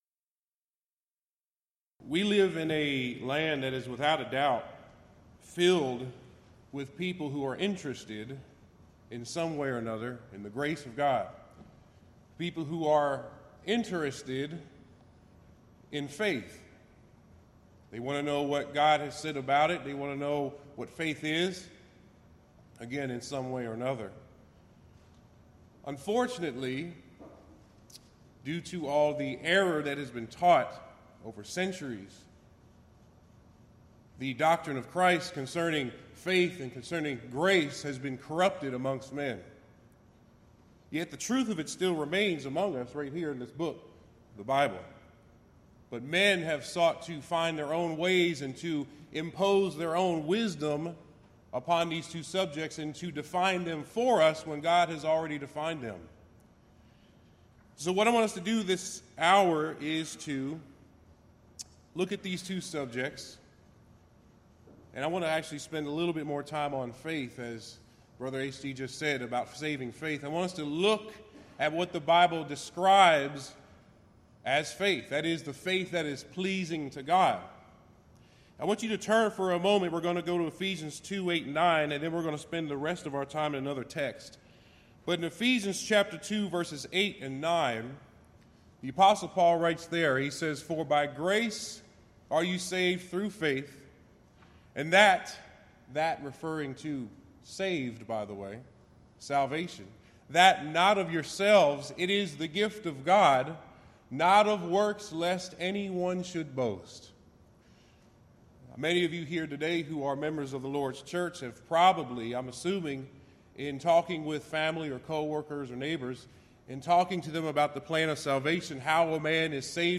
Event: 21st Annual Gulf Coast Lectures
lecture